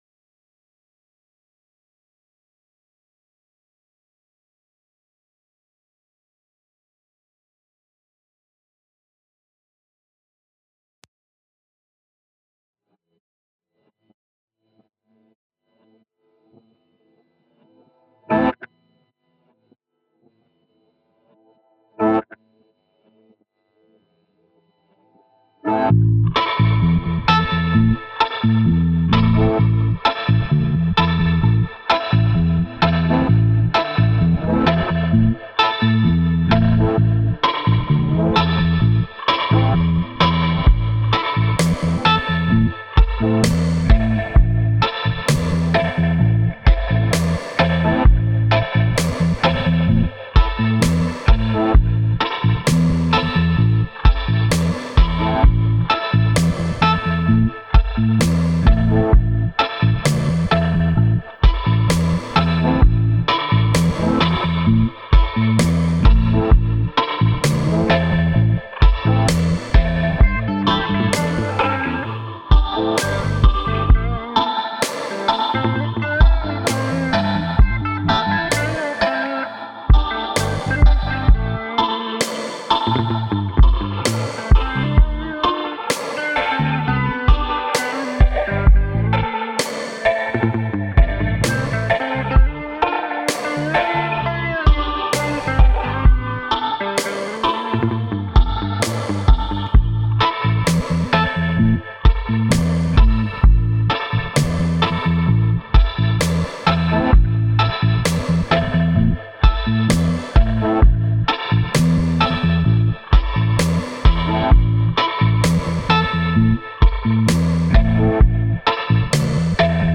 We received this submission of an incredibly produced and presented Dub Track titled “Dub No1”.